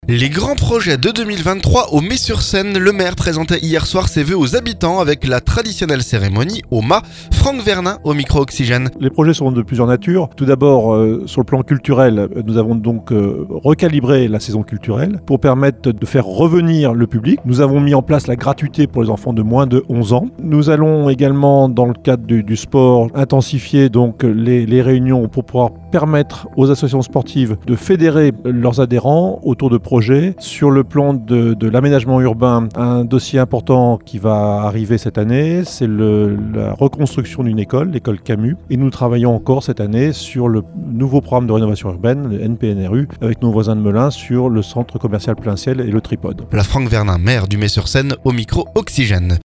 Les grands projets de 2023 au Mée sur Seine ! Le maire présentait mercredi soir ses vœux aux habitants avec la traditionnelle cérémonie, au Mas. Franck Vernin s'exprime au micro Oxygène.